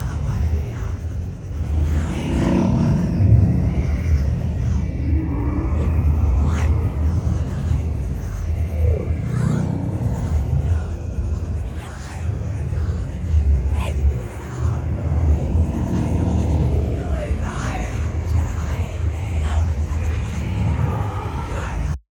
umbra_idle.ogg.bak